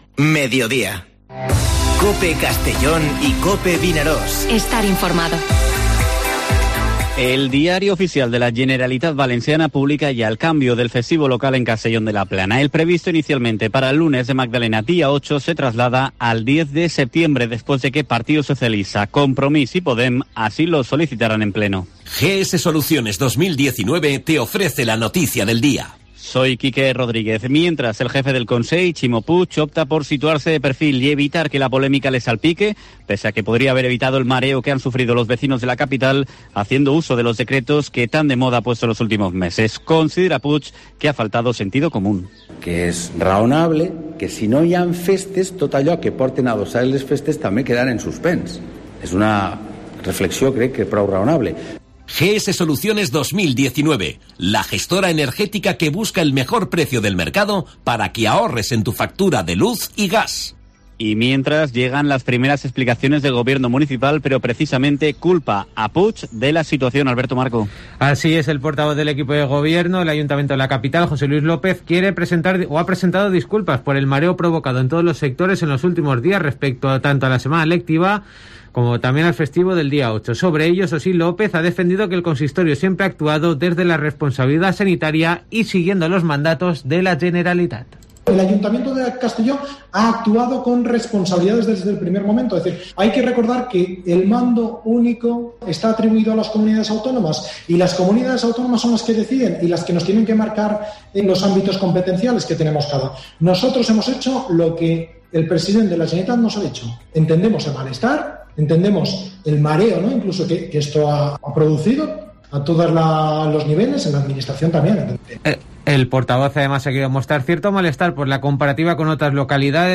Informativo Mediodía COPE en la provincia de Castellón (04/03/2021)